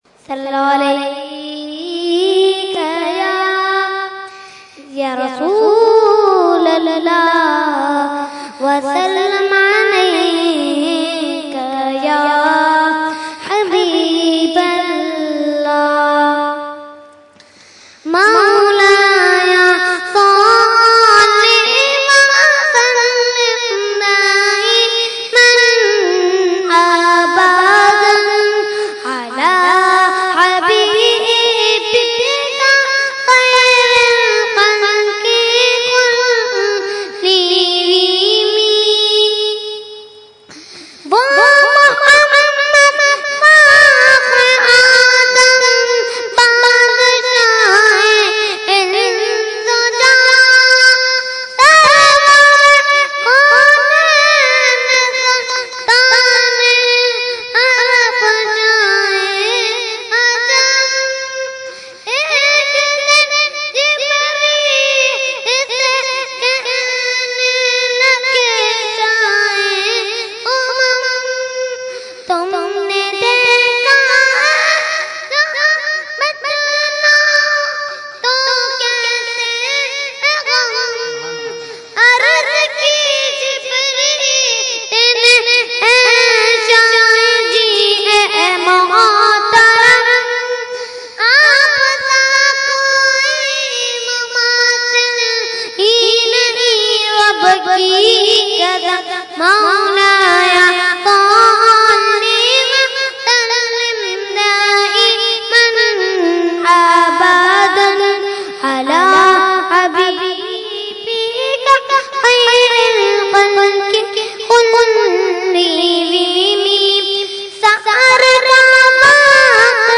Category : Naat | Language : UrduEvent : Urs Ashraful Mashaikh 2014